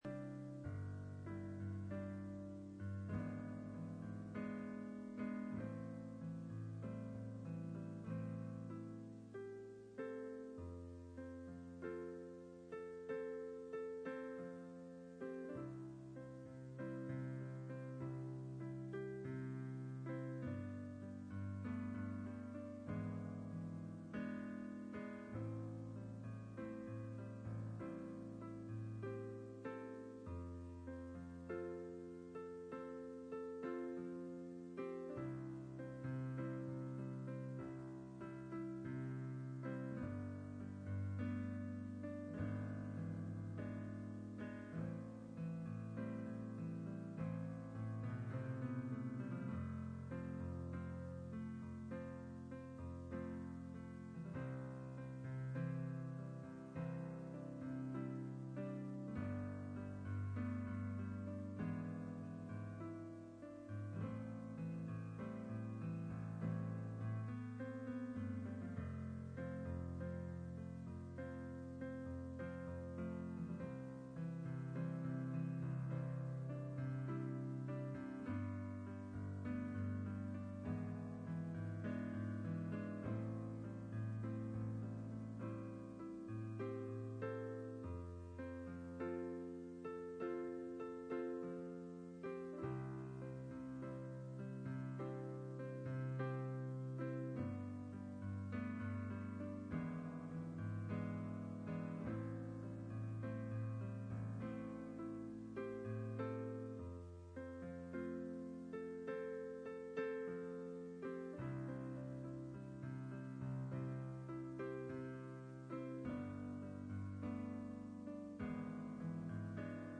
Series: Bible Study
Hebrews 10:1-15 Service Type: Midweek Meeting %todo_render% « Love Not The World